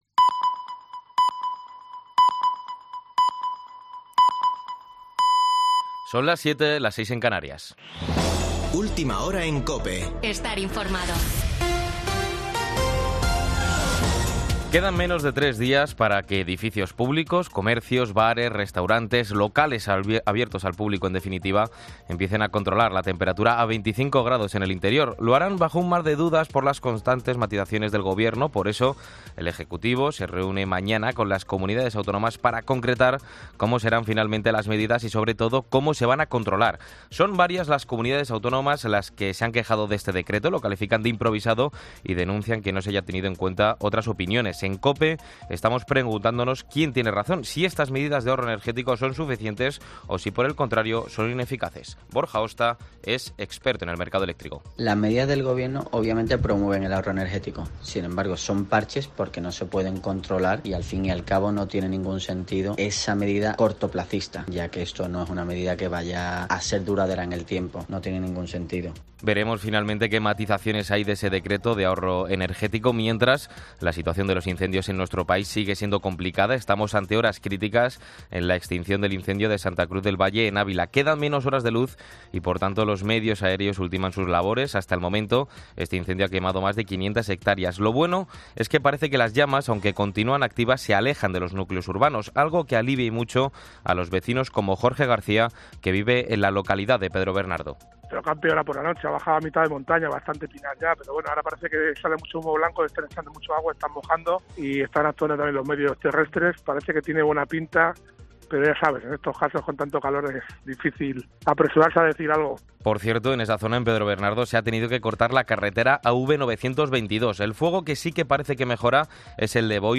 Boletín de noticias de COPE del 7 de agosto de 2022 a las 19.00 horas